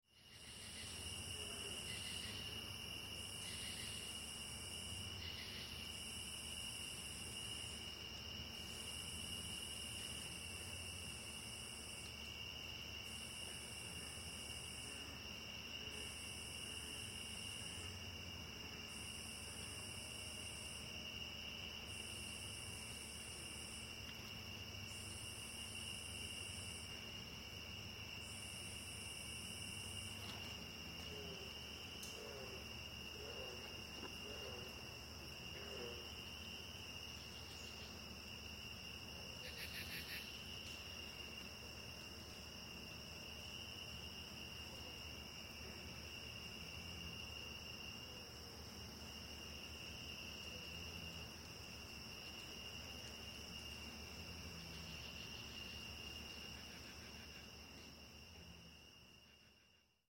Sounds_of_the_night_forest
• Category: Night